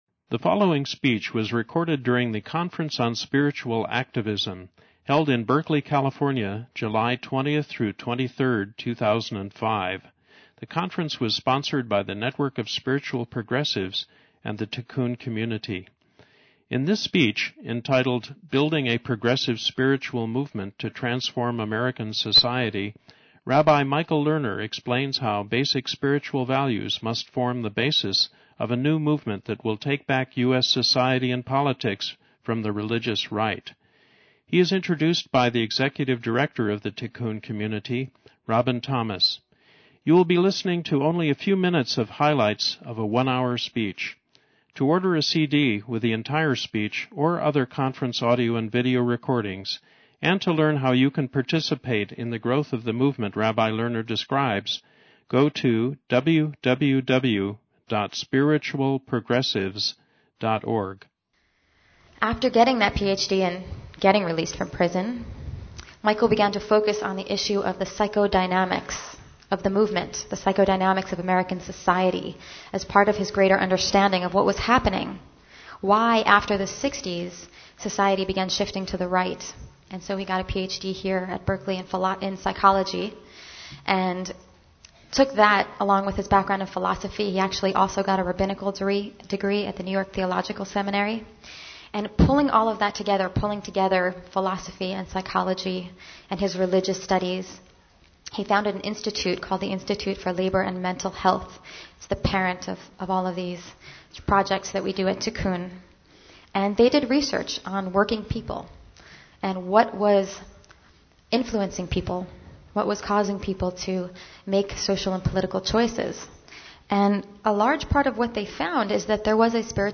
lerner_plenary_7-20_9pm_excerpts.mp3